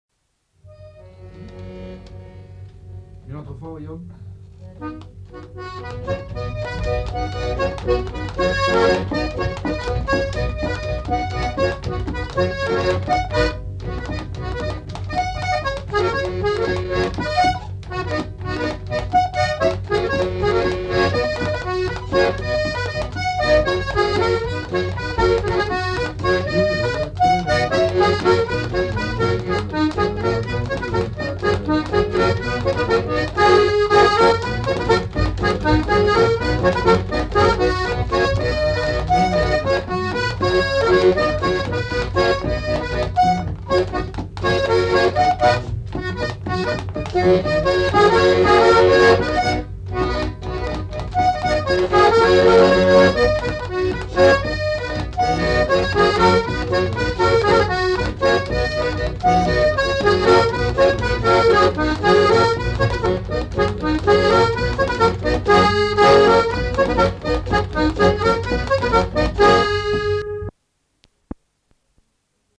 Aire culturelle : Savès
Lieu : Polastron
Genre : morceau instrumental
Instrument de musique : accordéon diatonique
Danse : gigue